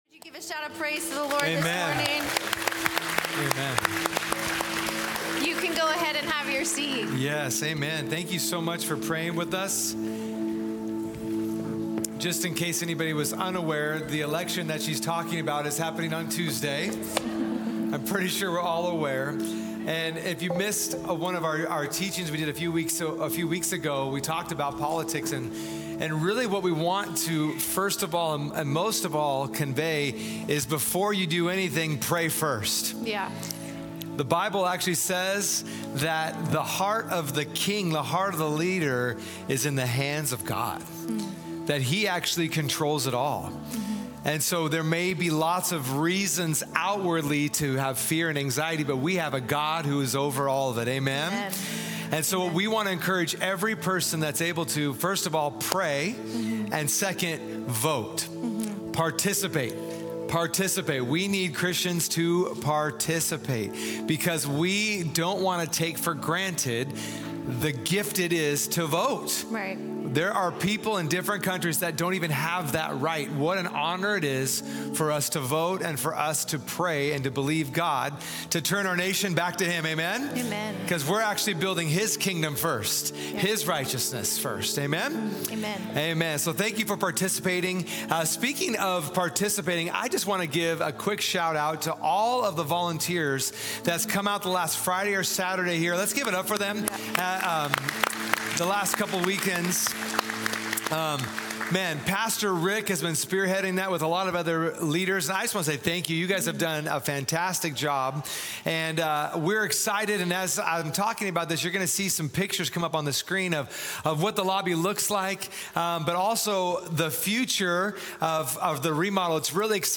Sunday Messages from Portland Christian Center The Treasure Principle, Part 4 Nov 03 2024 | 00:48:58 1x 00:00 / 00:48:58 Subscribe Share Spotify RSS Feed Share Link Embed